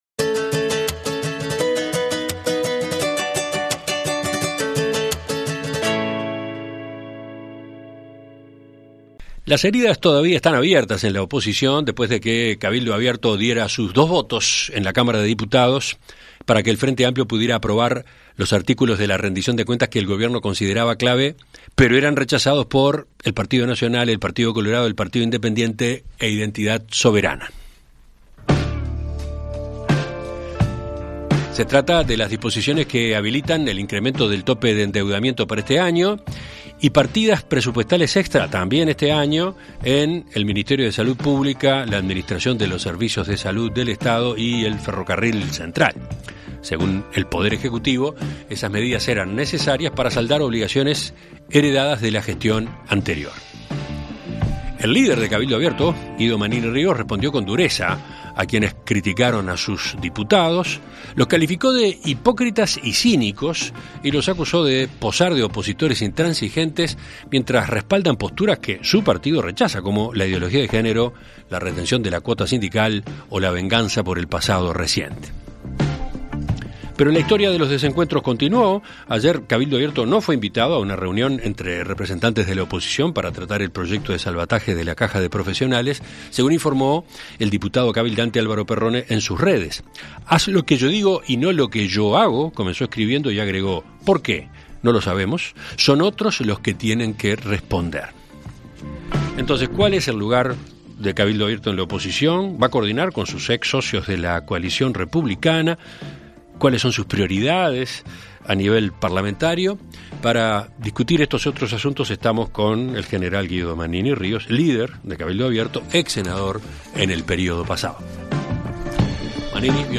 En Perspectiva Zona 1 – Entrevista Central: Sergio Botana - Océano
Conversamos con el senador Sergio Botana sobre la elección del Directorio del Partido Nacional. ¿Qué pasa en el otro gran sector del partido, Alianza País?